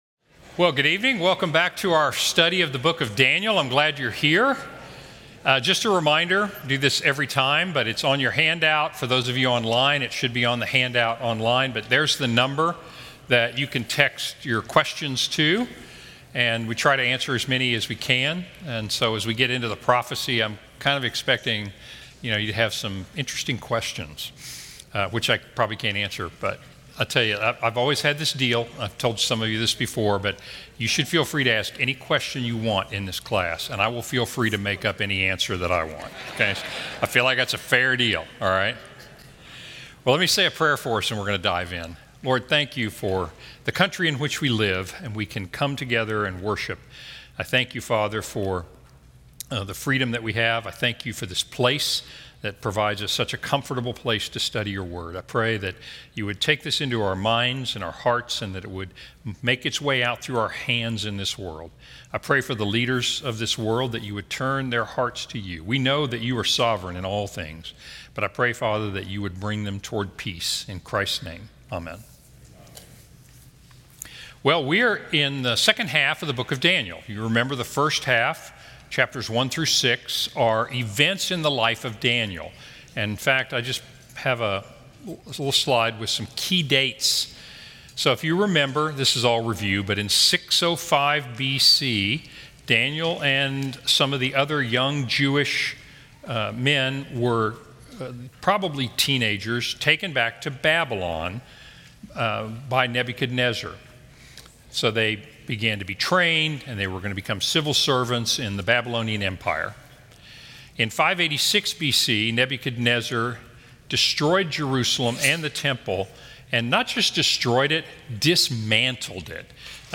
Wednesday Night Teaching